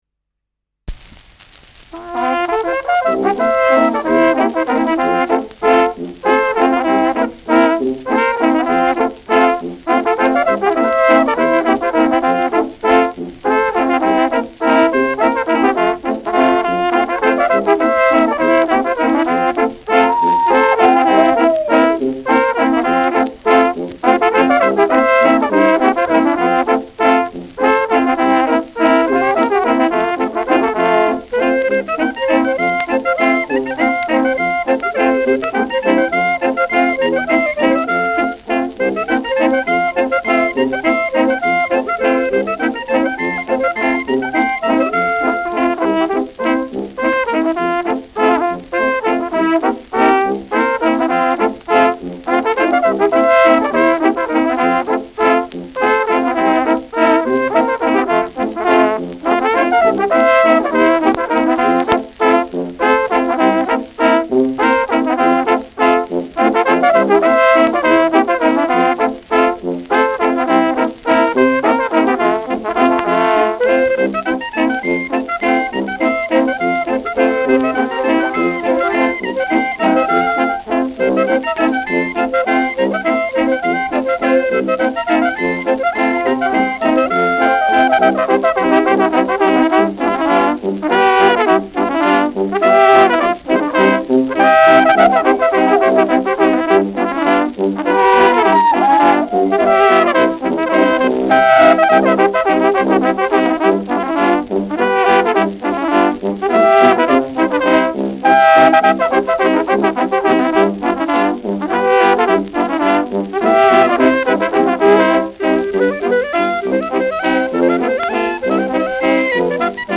Schellackplatte
Tonrille: Abrieb
leichtes Knistern
Fränkische Bauernkapelle (Interpretation)